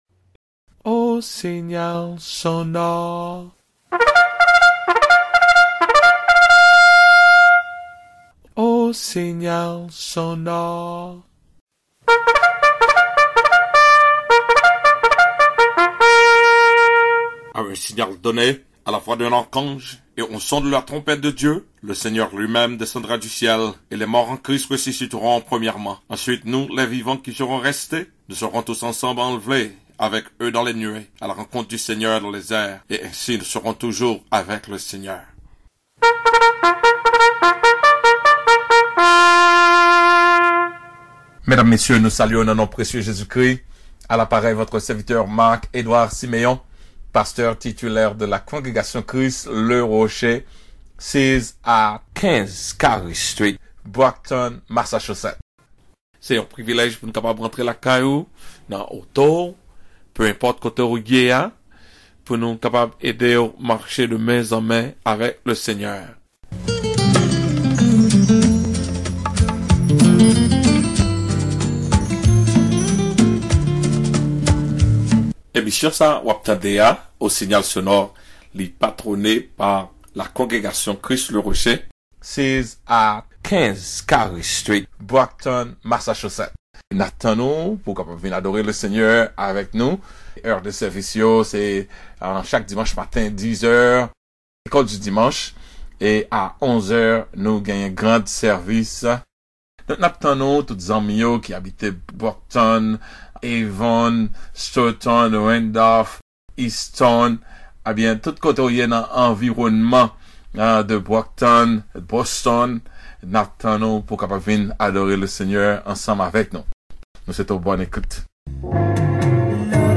click here to download the sermon LA COURSE DU CHRETIEN VERS LA PERFECTION